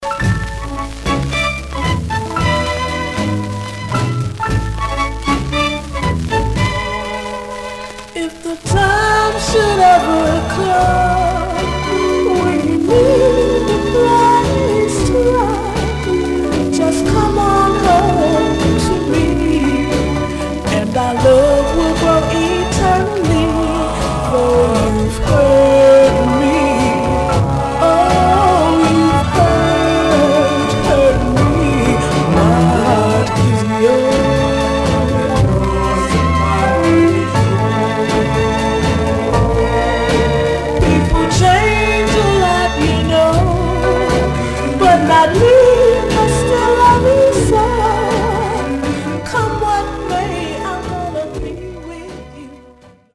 Much surface noise/wear
Mono